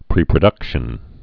(prēprə-dŭkshən)